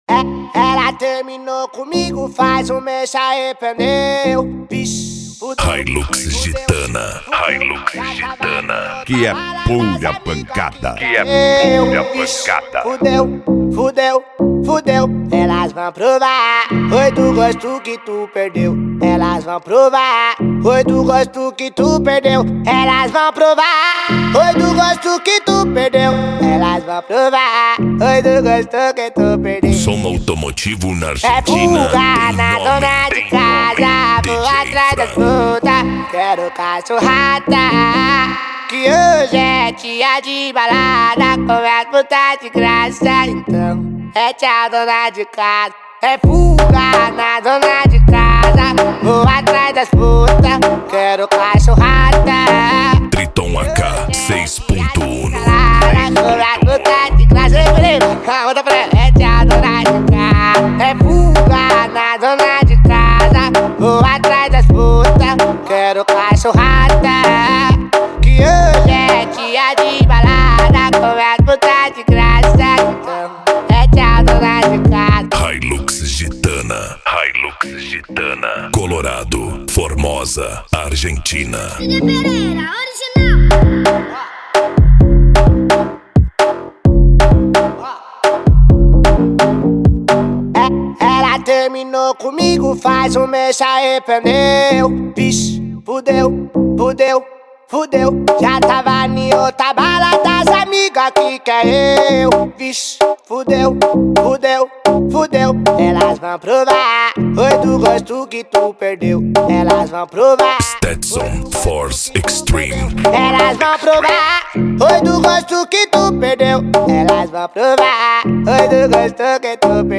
Bass
PANCADÃO